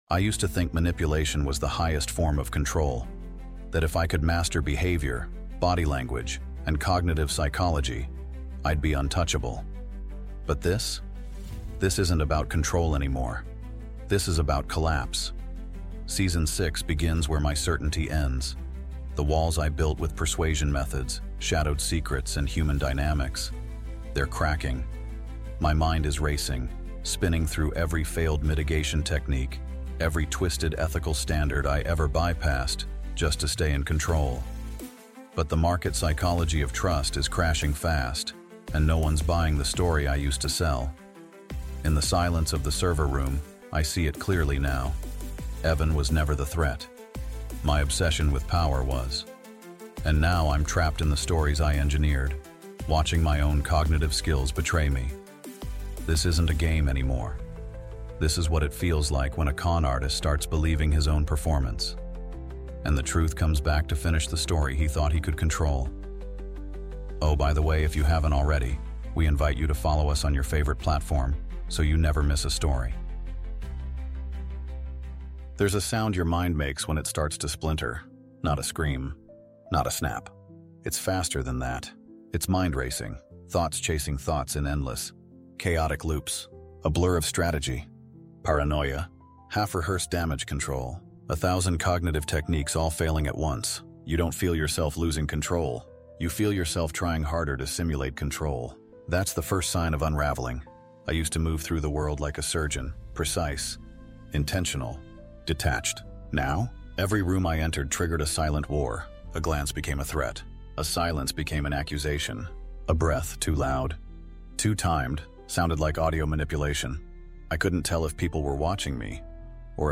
Inside the Mind of a Master Manipulator |The Unraveling | Audiobook